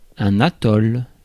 Ääntäminen
Ääntäminen France: IPA: [ɛ̃.n‿a.tɔl] Tuntematon aksentti: IPA: /a.tɔl/ Haettu sana löytyi näillä lähdekielillä: ranska Käännös 1. atoll Suku: m .